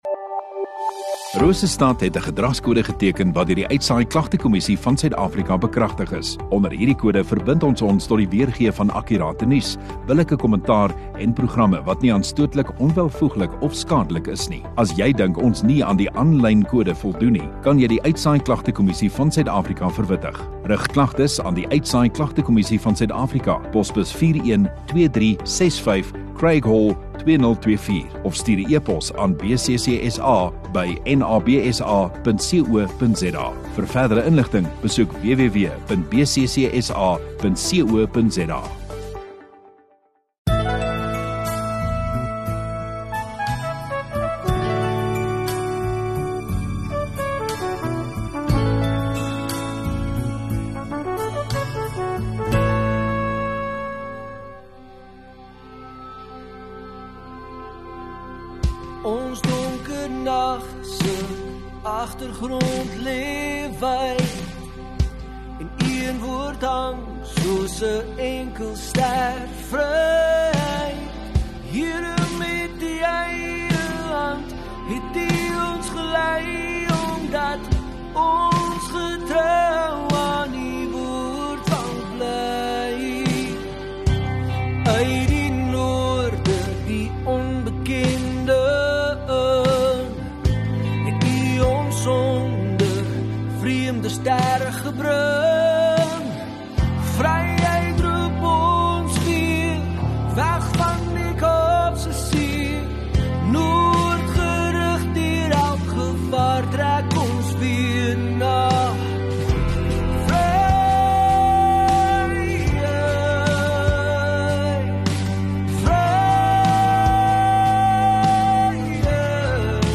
17 Mar Sondagoggend Erediens